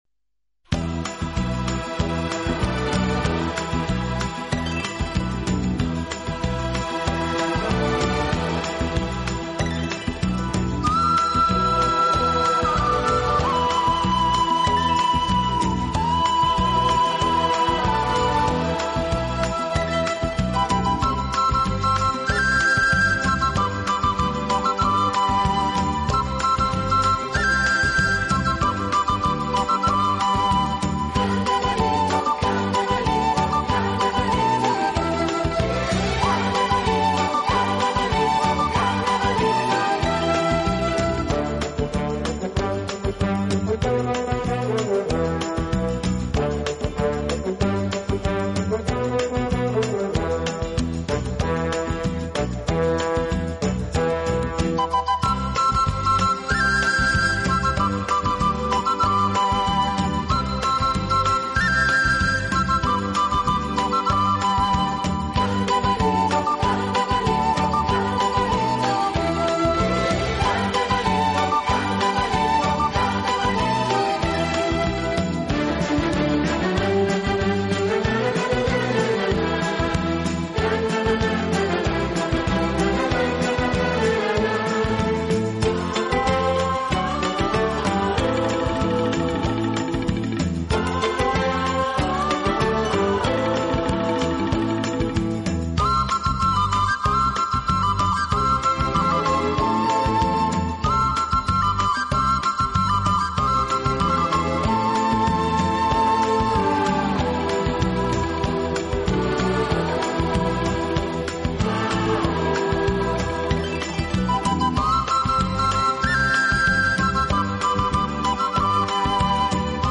【轻音乐】
方式，尤其是人声唱颂的背景部分，似乎是屡试不爽的良药。
有动感，更有层次感；既有激情，更有浪漫。